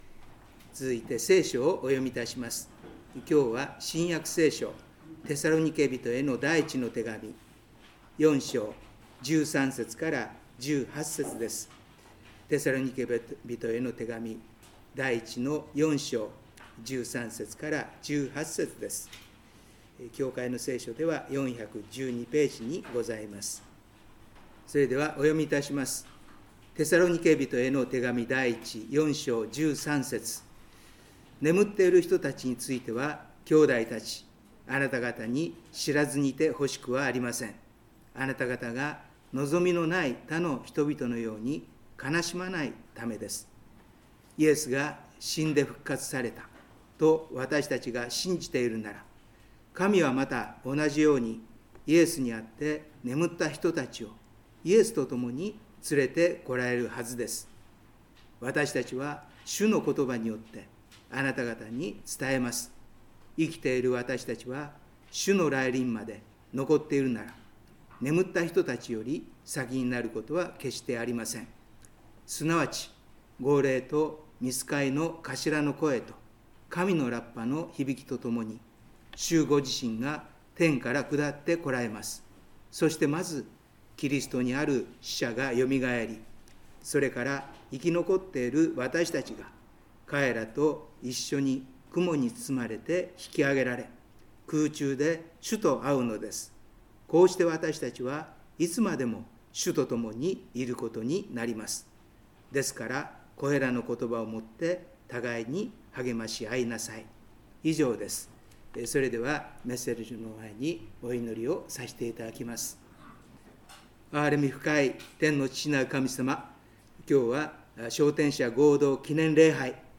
召天者合同記念礼拝